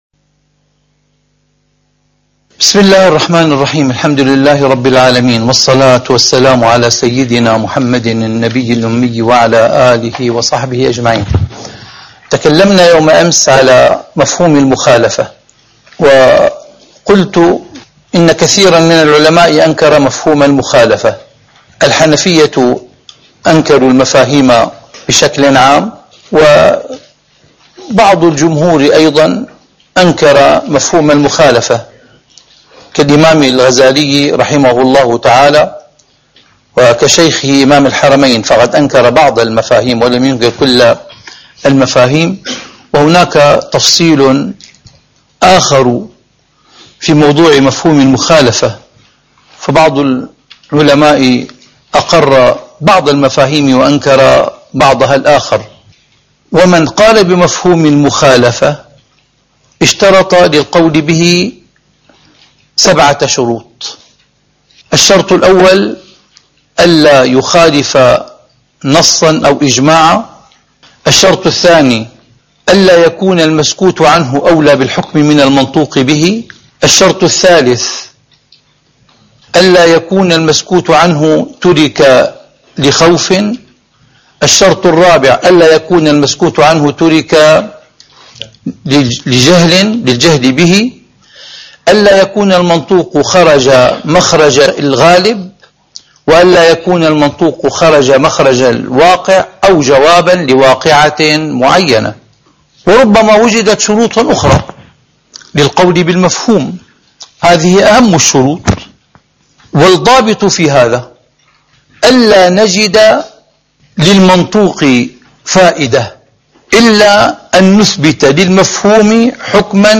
أصول الفقه / الدرس السادس: مفهوم المخالفة (2)، الأوامر والنواهي (1)